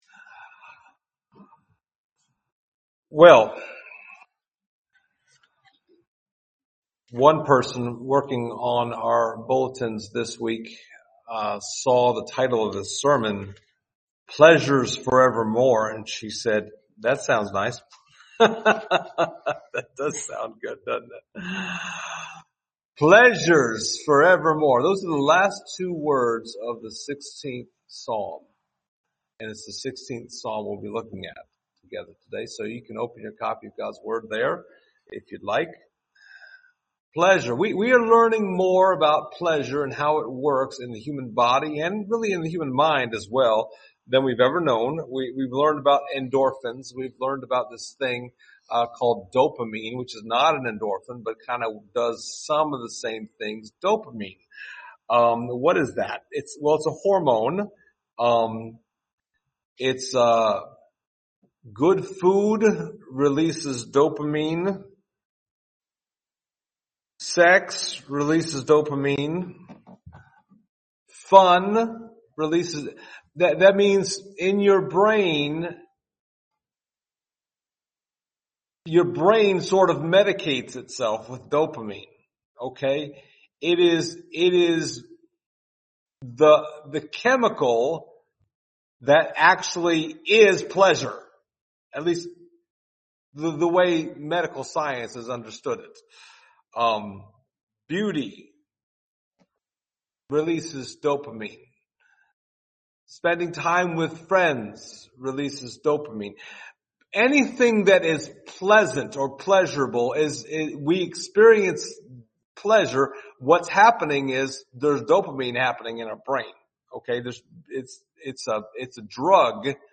Summer Psalms Passage: Psalm 16 Service Type: Sunday Morning Topics